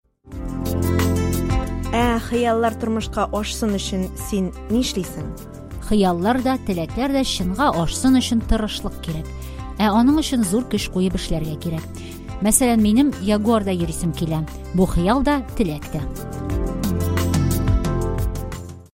Послушайте три аудио, где журналист спрашивает собеседника о желаниях и способах их достижения.